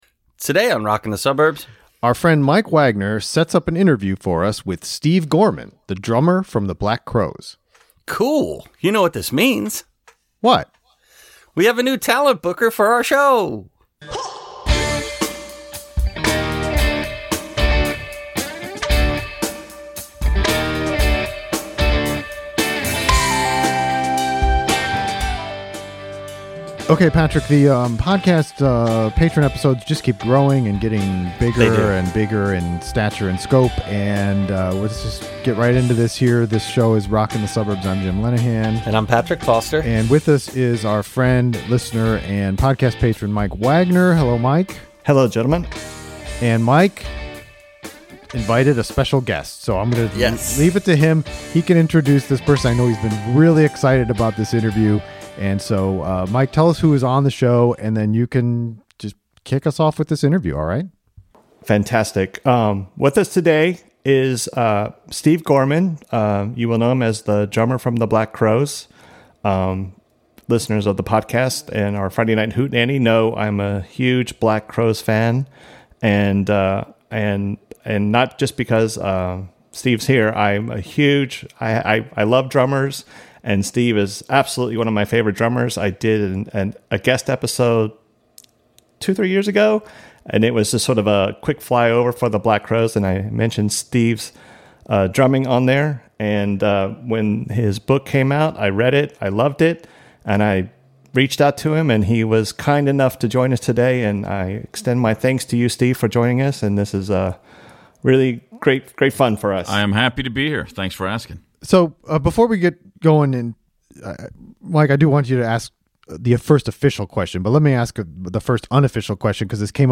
Black Crowes' Steve Gorman - Interview